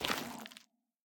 Sculk_step2.ogg.ogg